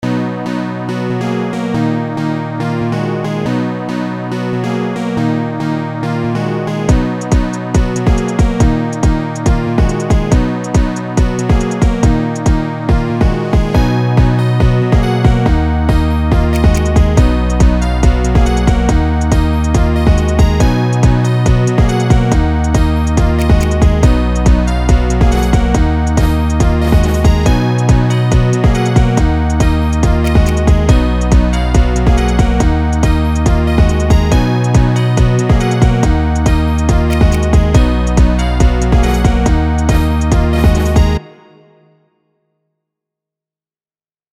Жанр: Hip-hop,Trap